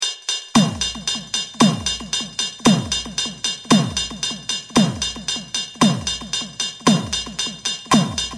Ride + Synth Drum, 132 KB
tb_ride_synth_drum.mp3